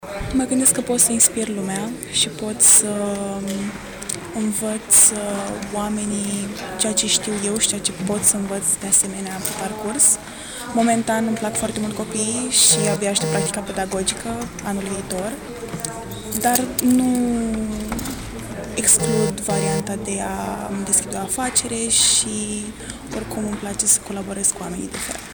studenta-fizica-an-2.mp3